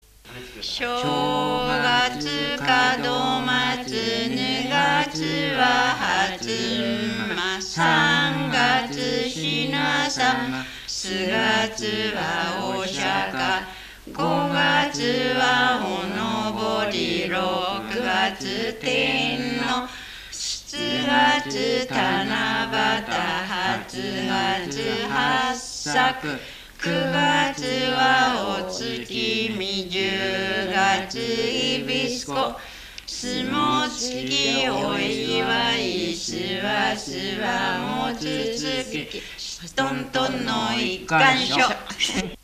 羽根つき歌 遊戯歌